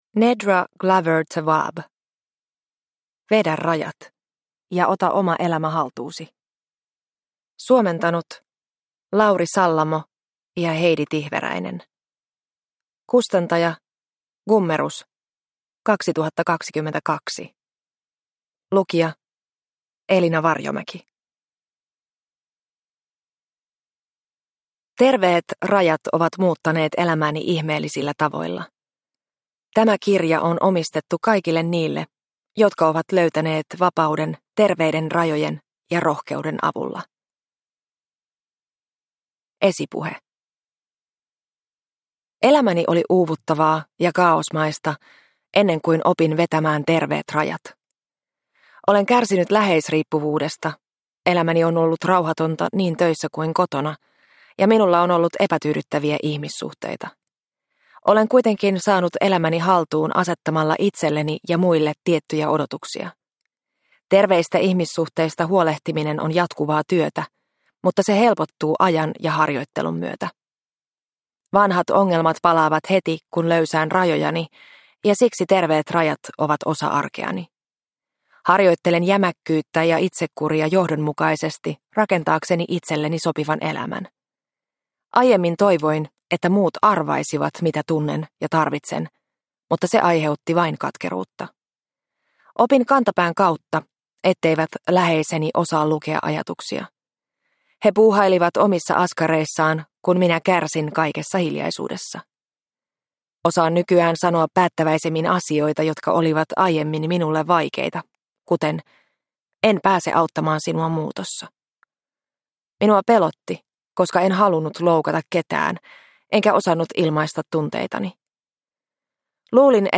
Vedä rajat – Ljudbok – Laddas ner